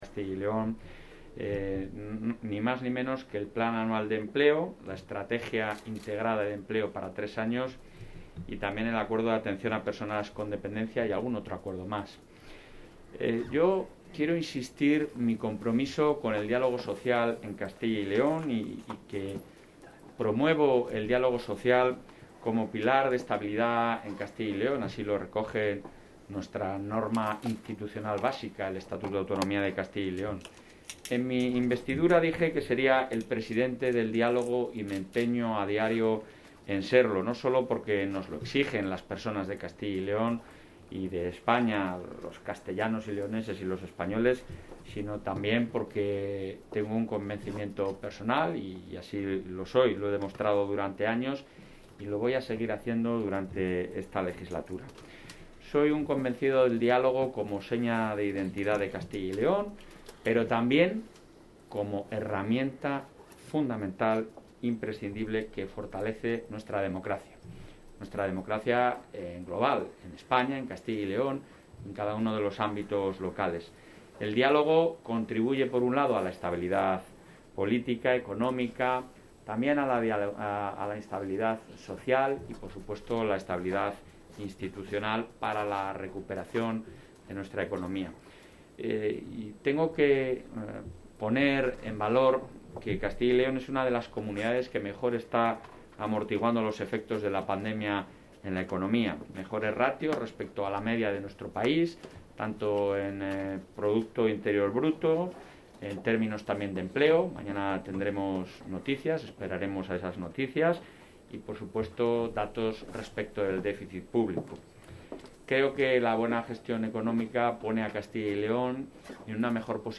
Intervención del presidente.
Fernández Mañueco ha defendido que el diálogo contribuye a la estabilidad política, económica, social e institucional para la recuperación. Así lo ha apuntado durante su intervención en la inauguración de la reunión de presidentes de los Consejos Económicos y Sociales de España que se celebra en Valladolid, en la que ha valorado la colaboración de esta institución en Castilla y León, que cumple 30 años, además de agradecer el trabajo de las organizaciones y expertos que la conforman.